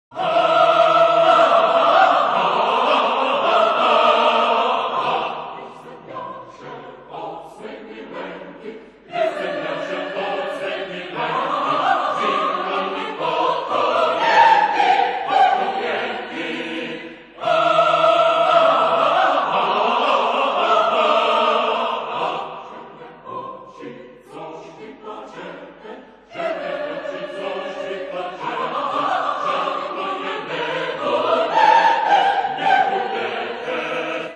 adaptation of traditional folk song